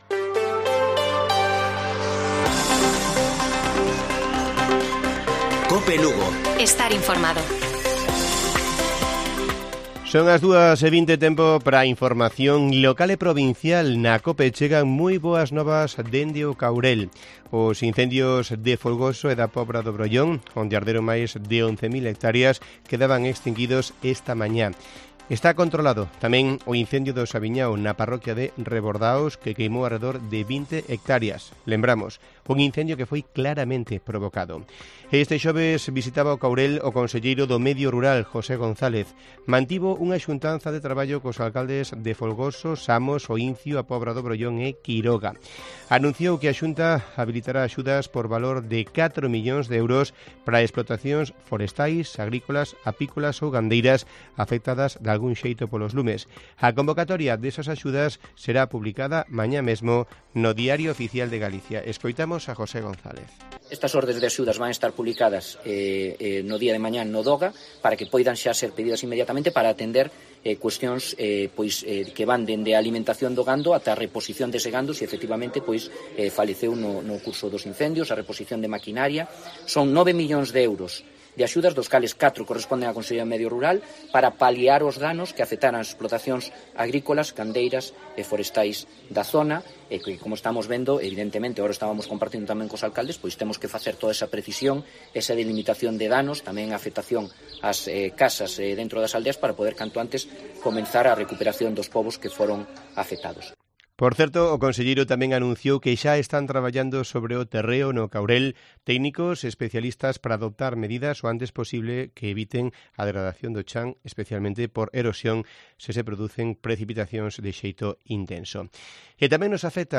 Informativo Mediodía de Cope Lugo. 28 DE JULIO. 14:20 horas